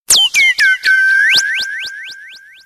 • Качество: 320, Stereo
громкие
звонкие
космическая тема
Космический звук на смс